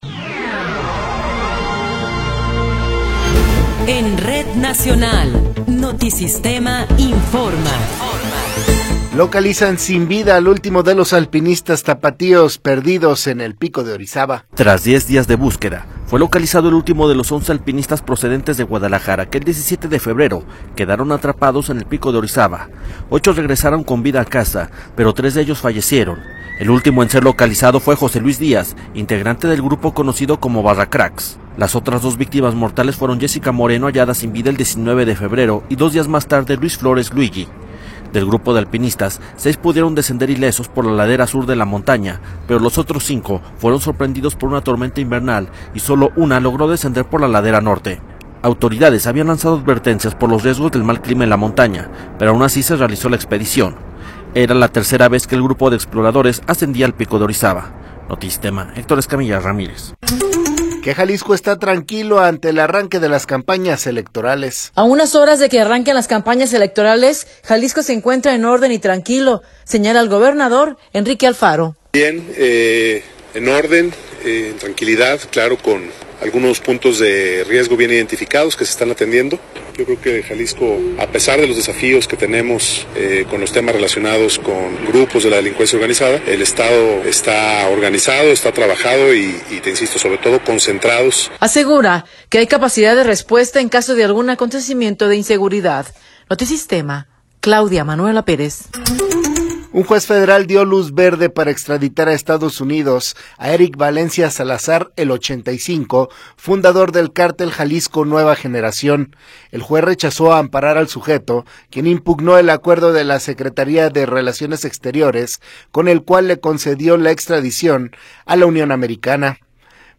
Noticiero 19 hrs. – 27 de Febrero de 2024
Resumen informativo Notisistema, la mejor y más completa información cada hora en la hora.